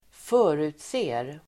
Uttal: [²f'ö:ru:tse:r]